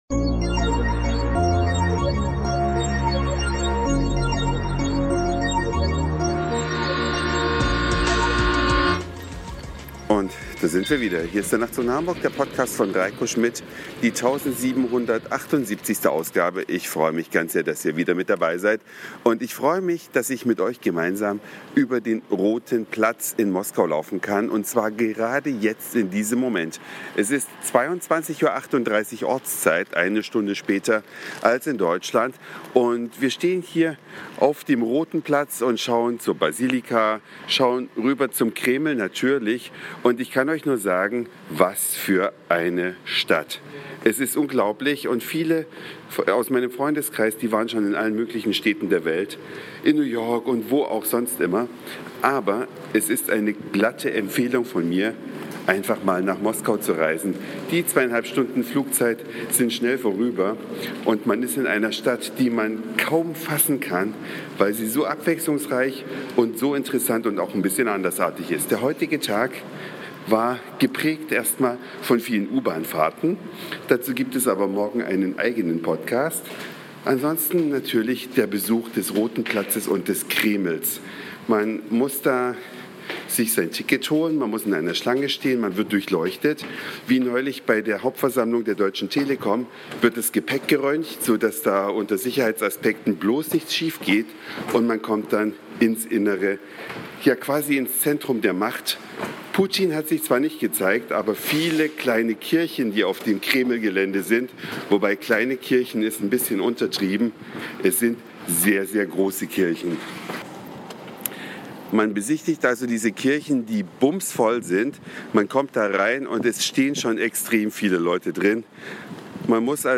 Eine Reise durch die Vielfalt aus Satire, Informationen, Soundseeing und Audioblog.
Live unterwegs in der Stadt Roter Platz und Kreml, Gorki Park, GUM,